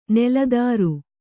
♪ neladāru